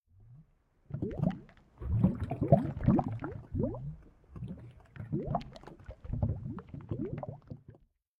molten.ogg